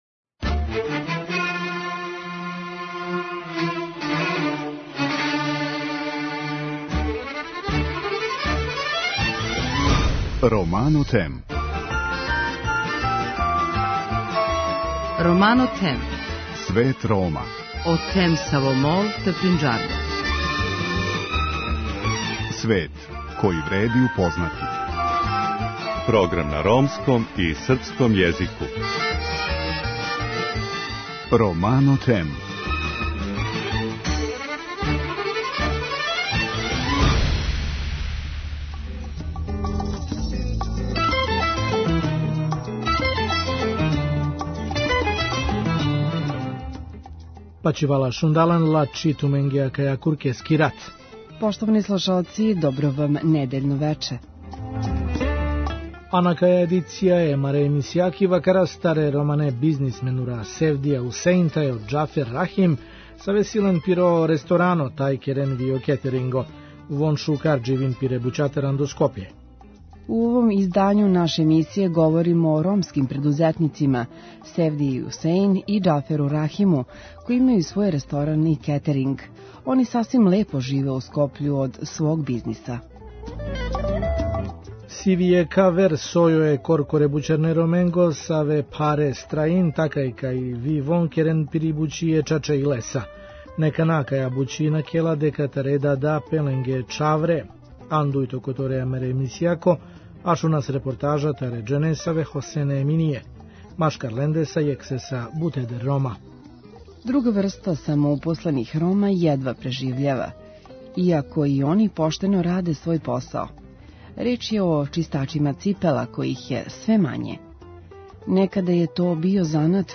У другом делу емисије слушамо репортажу о чистачима ципела међу којима је одувек било много Рома.